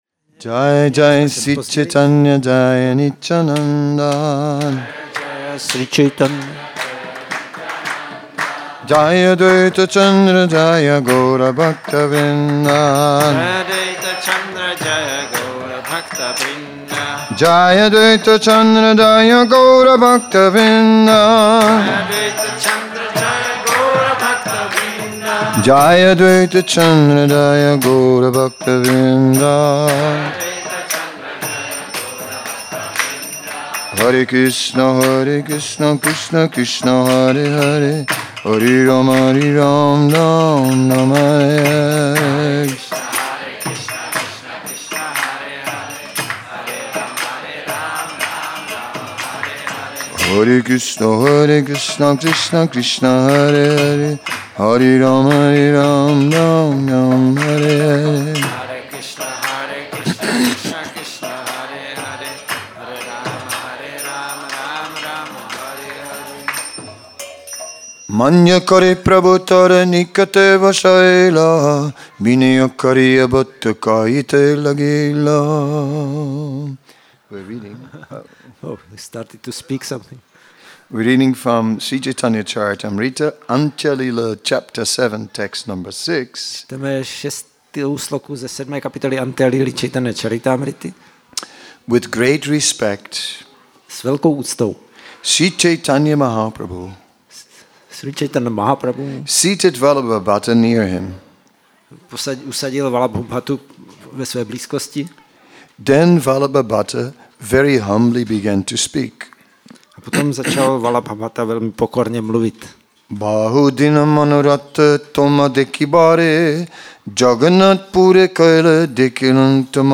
Přednáška večerní – Šrí Šrí Nitái Navadvípačandra mandir